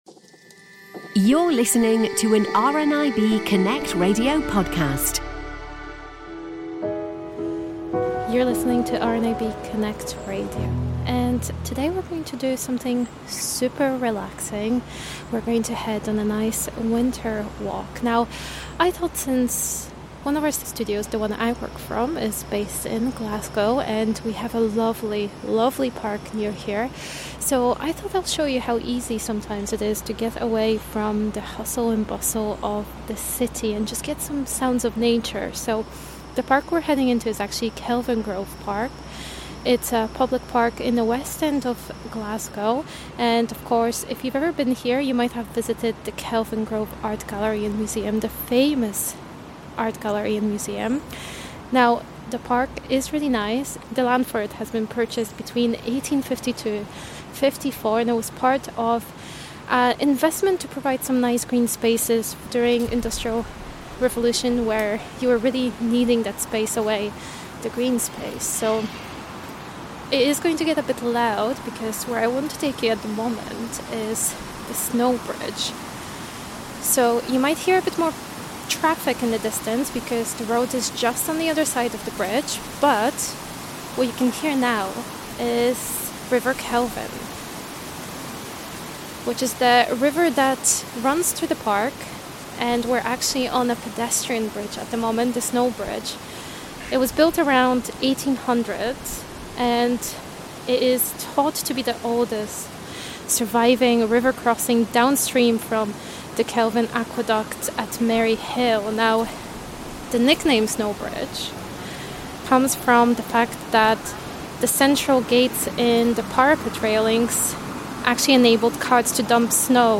A Winter Stroll Through Kelvingrove Park